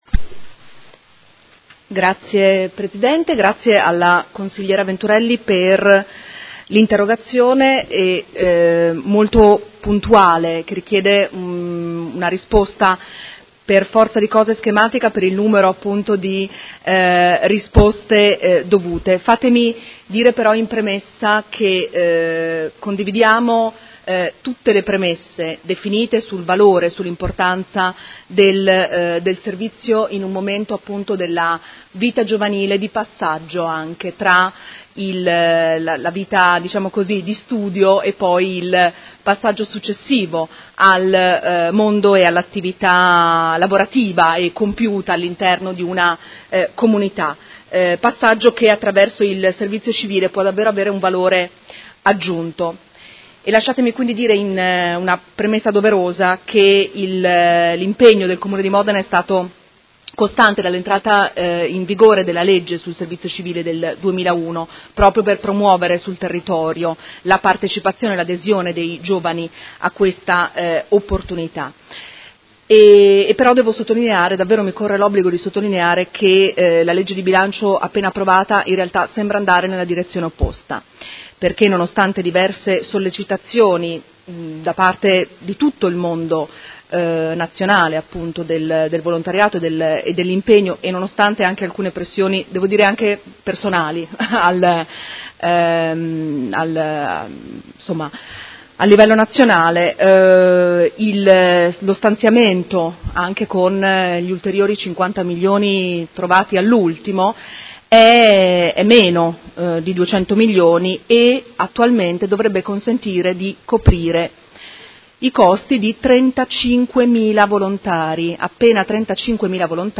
Seduta del 10/01/2019 Risponde. Interrogazione della Consigliera Venturelli (PD) avente per oggetto: Servizio Civile: un’occasione di crescita umana e professionale
Seduta del 10 gennaio 2019